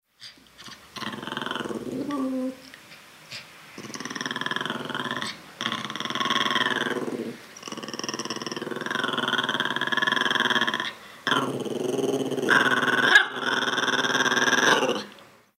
grunido.mp3